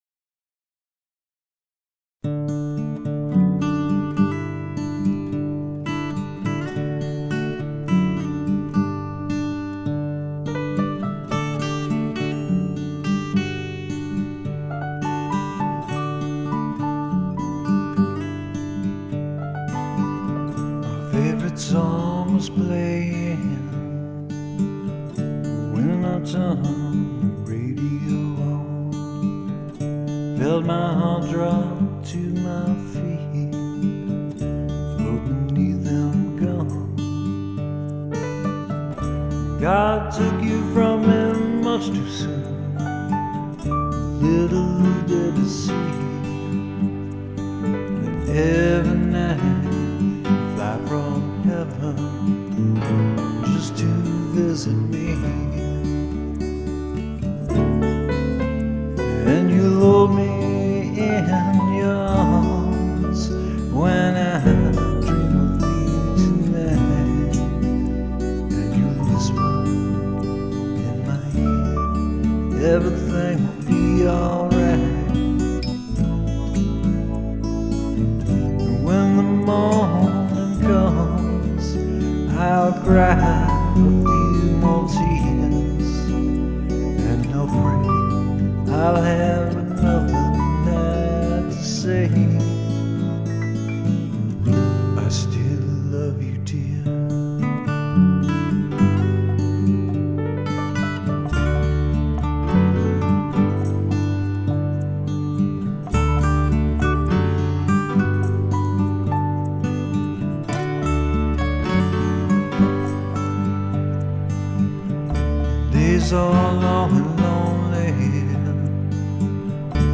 Vocals
A very melancholy song